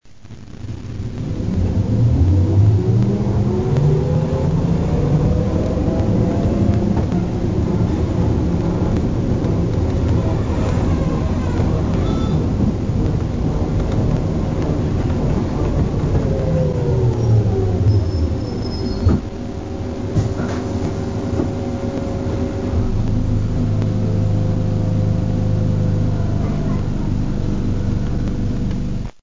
Obus 202 fährt in der Schenkenbergstraße in Richtung Esslingen die Haltestelle Bahnhof Mettingen an (02.05.1992)
Zu hören ist die Anfahrt, das Befahren einer Einlaufweiche mit Spannungsunterbrechung (Summer), das Schützenschaltwerk beim Bremsen, das Türöffnen und schließlich das Einschalten des Kompressors.
trolley_202.mp3